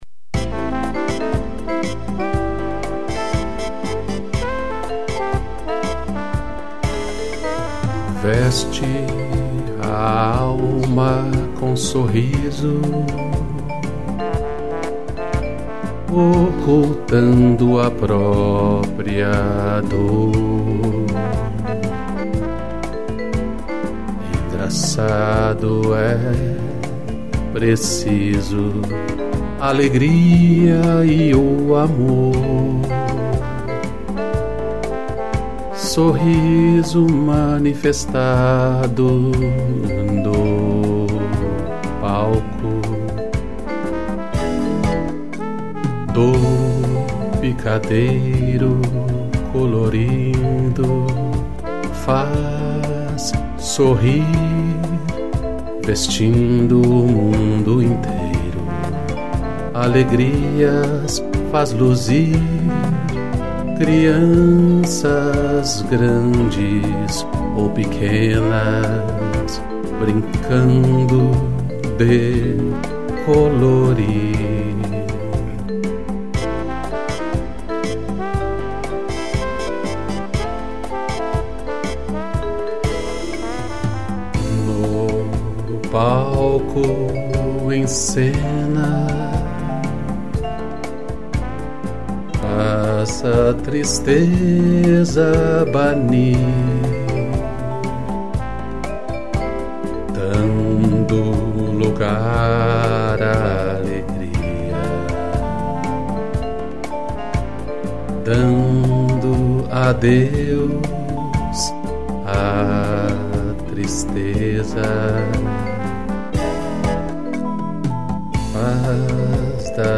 Voz
piano e trombone